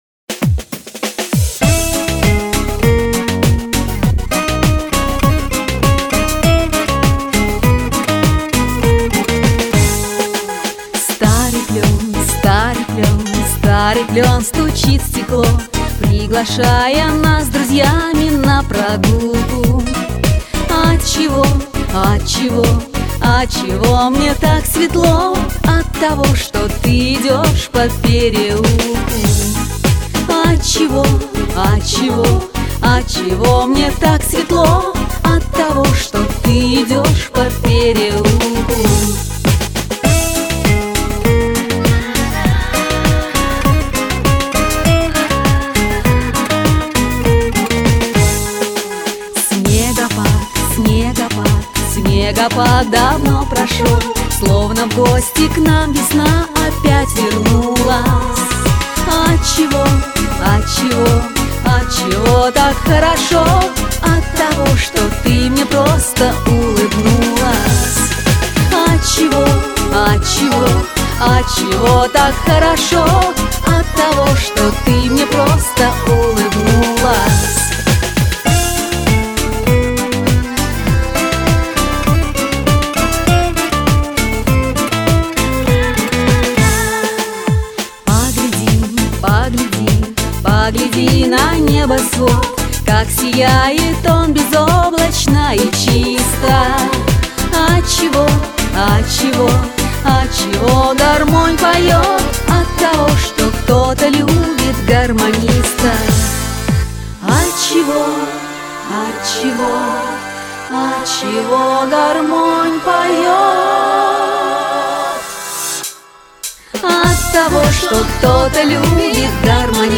Genre: Pop
Style: Ballad, Schlager, Vocal